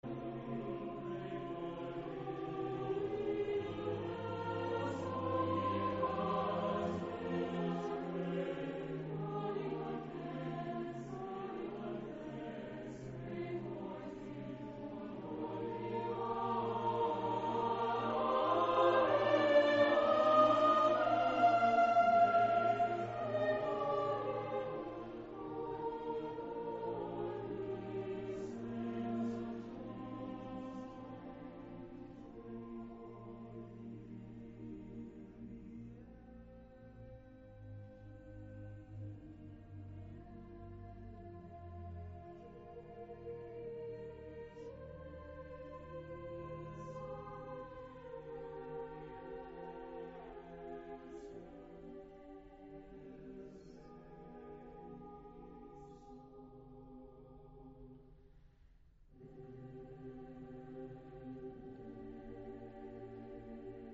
古典音樂